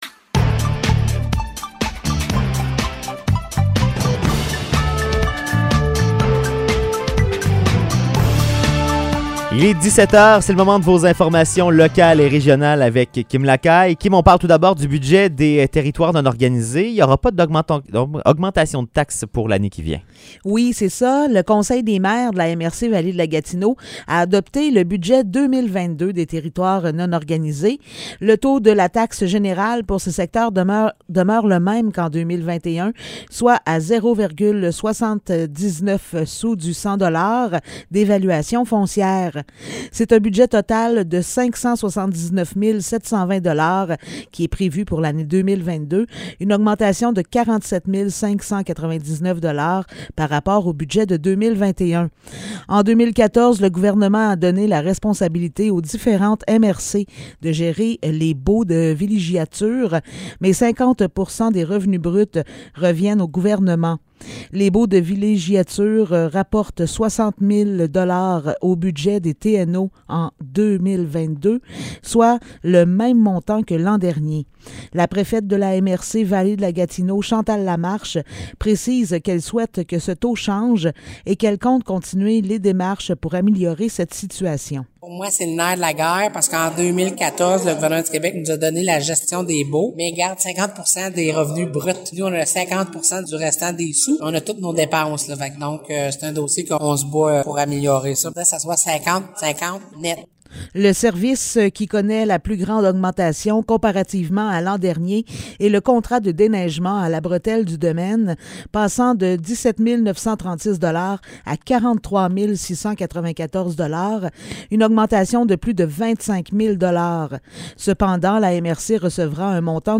Nouvelles locales - 16 décembre 2021 - 17 h